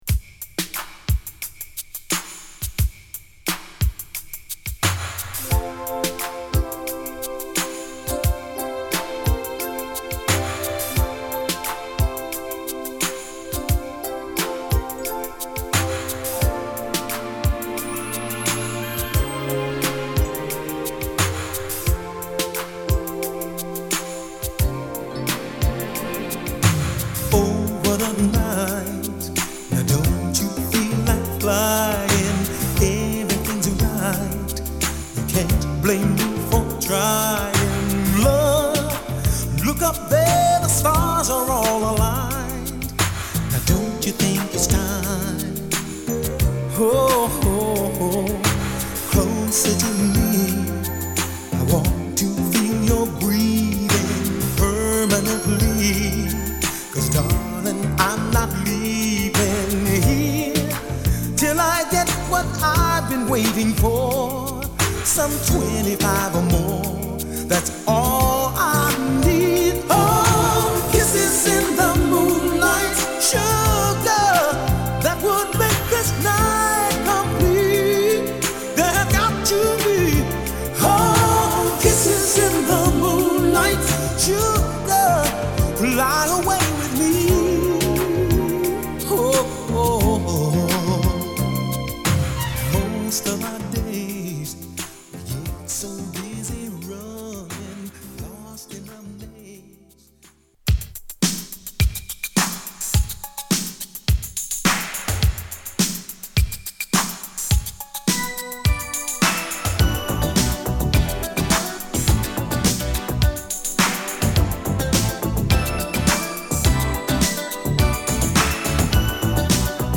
メロウ〜アーバンな仕上がりです。